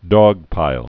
(dôgpīl, dŏg-) Slang